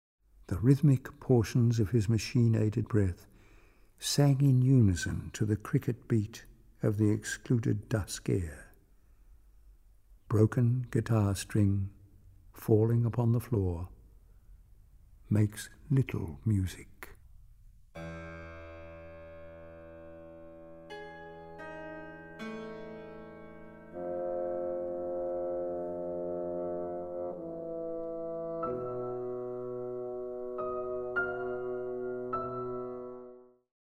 Studio 420, Ferry Road, Brisbane, 6 – 8 February 2012